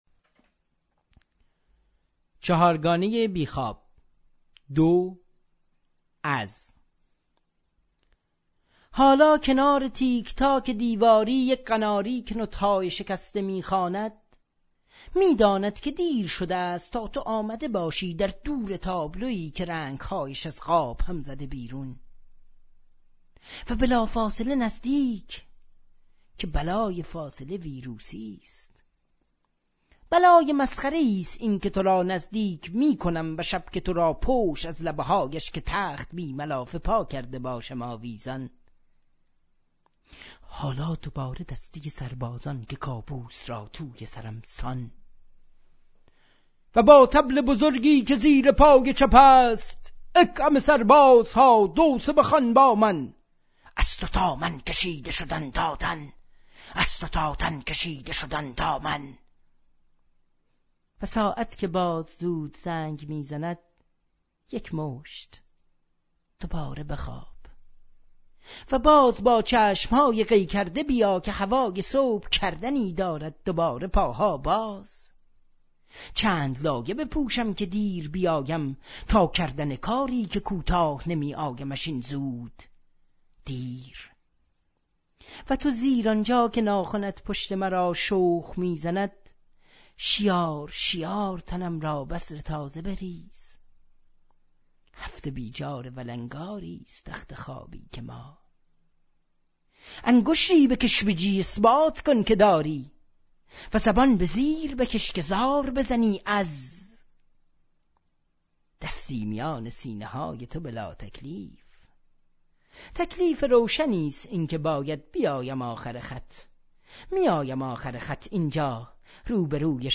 صدای شاعر